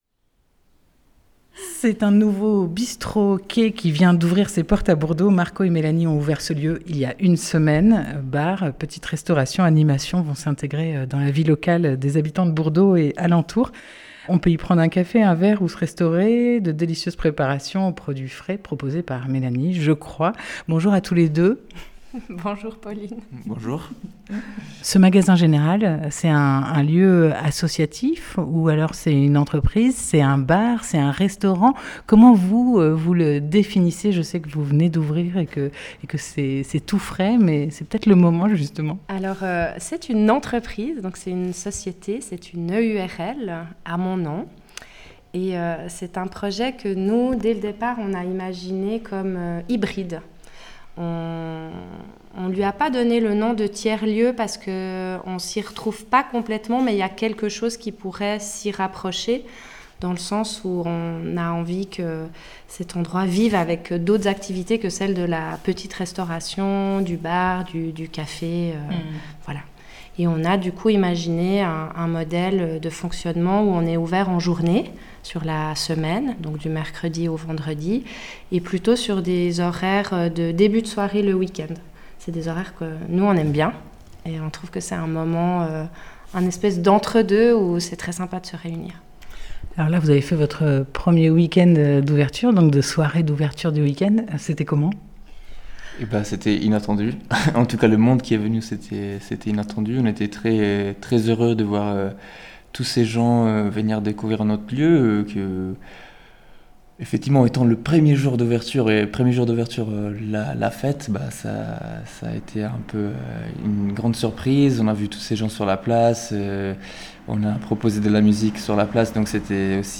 28 mai 2023 12:14 | Interview, la belle sais'onde